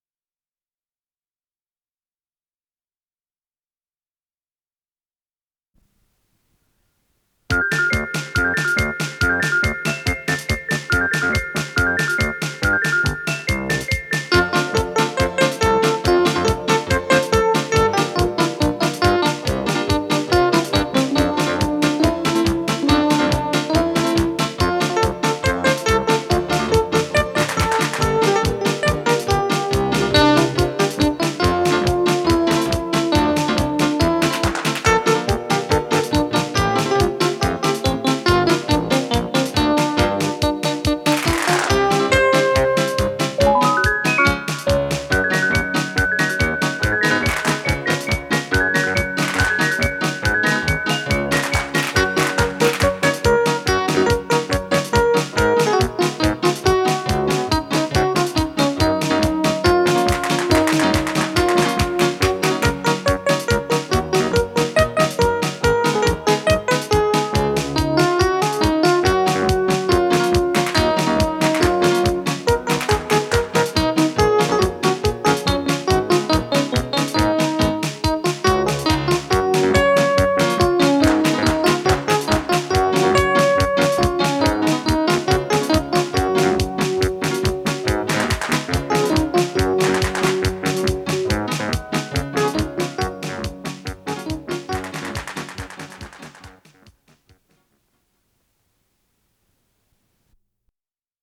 синтезатор
бас-гитара
ударные
ВариантДубль моно